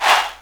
SouthSide Chant (2).wav